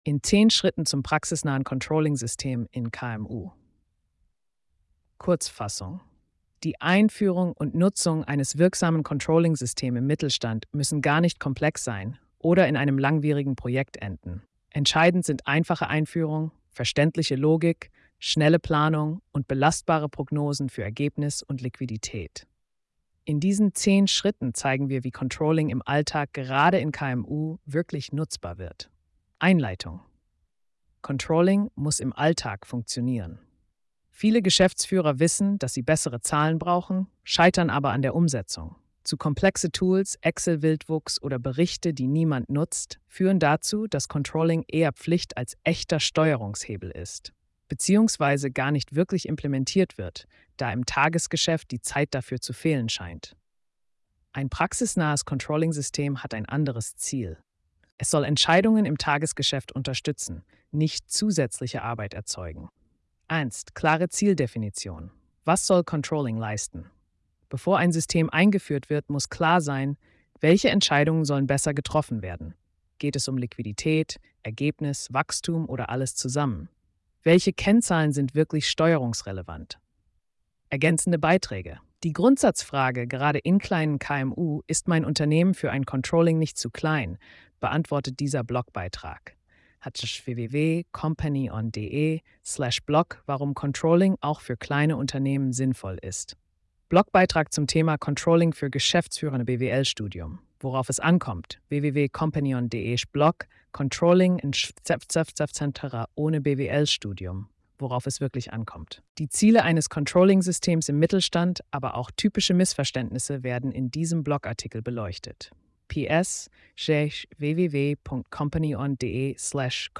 Symbolbild Sprachausgabe